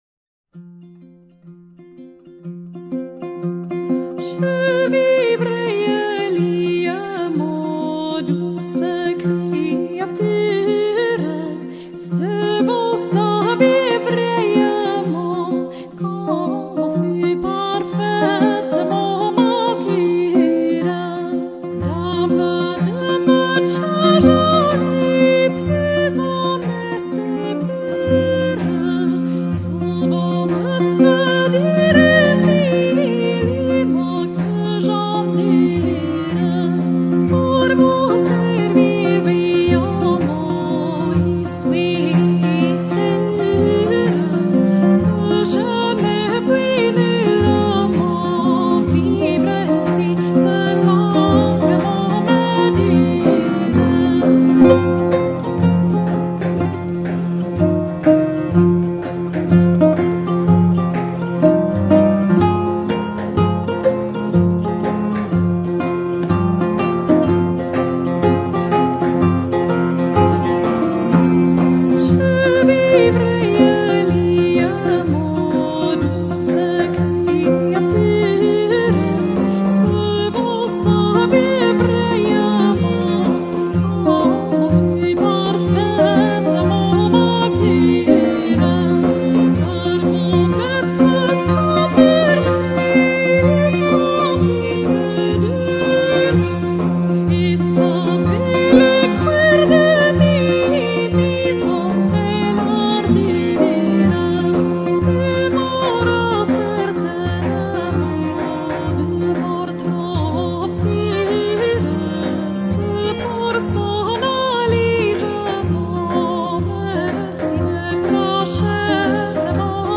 Période : XIVe siècle, Moyen Âge
Le virelai de Guillaume de Machaut
Elle nous offre, ici, une version très épurée du virelai de Guillaume de Machaut, sans fioriture et soutenue presque uniquement par sa très belle prestation vocale.